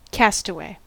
Ääntäminen
Ääntäminen US
IPA : /ˈkæ.stə.weɪ/